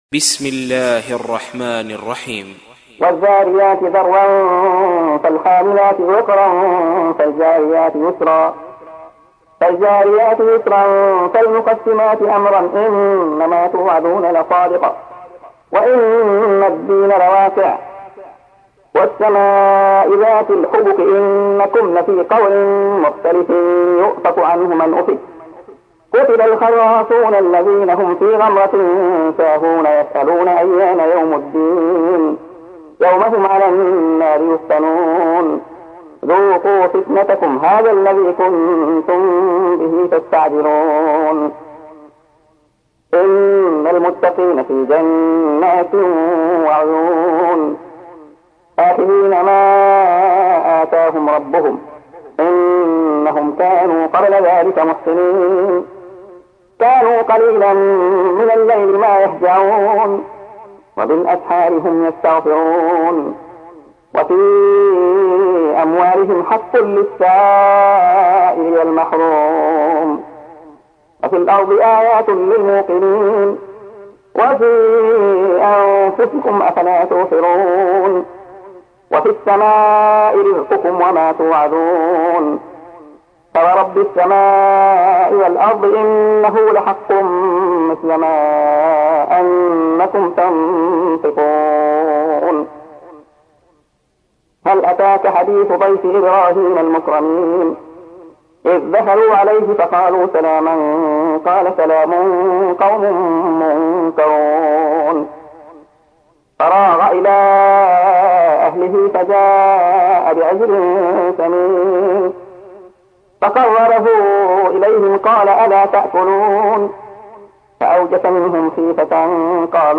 تحميل : 51. سورة الذاريات / القارئ عبد الله خياط / القرآن الكريم / موقع يا حسين